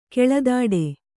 ♪ keḷadāḍe